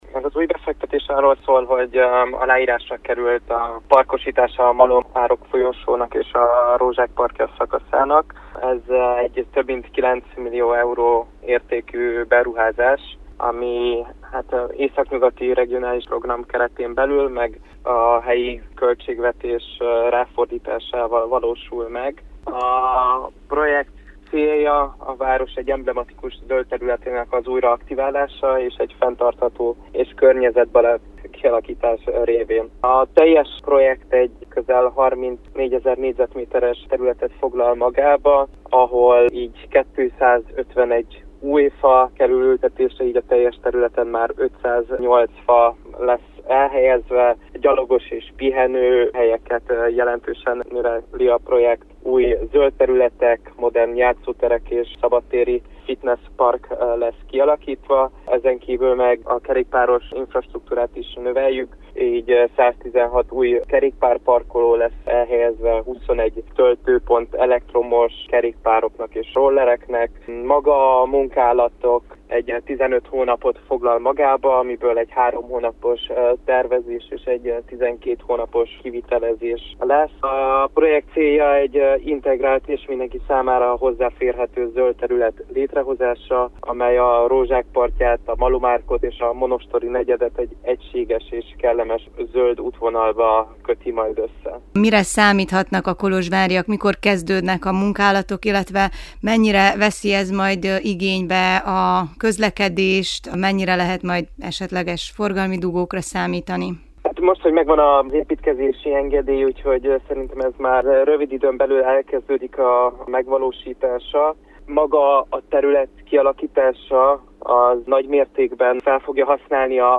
Andacs Zsolt Levente városi tanácsos mondta el a részleteket rádiónknak.